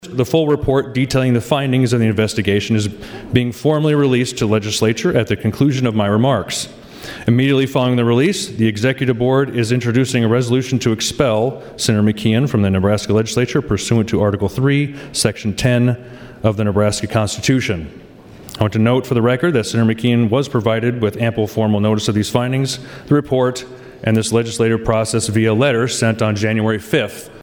HANSEN GAVE HIS FELLOW SENATORS A COPY OF THE INVESTGATION REPORT: